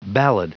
Prononciation du mot ballad en anglais (fichier audio)
Prononciation du mot : ballad